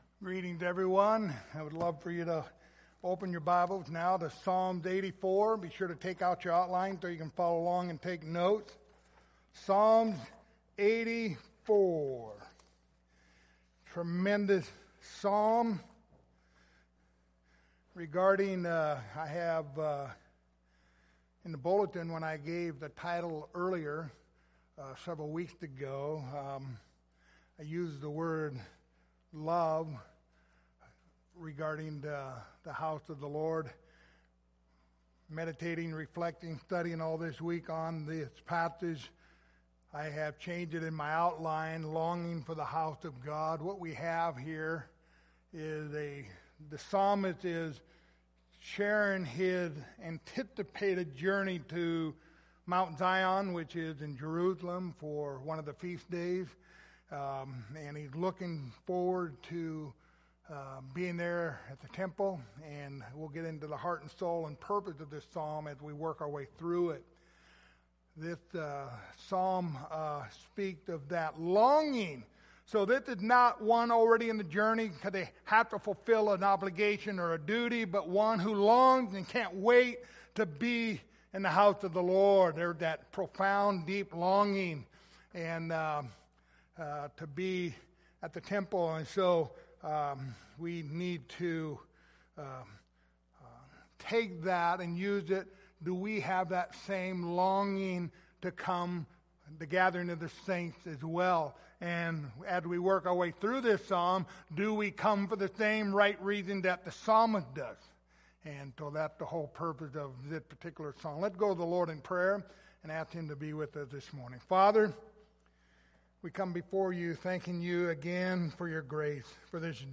Passage: Psalms 84:1-12 Service Type: Sunday Morning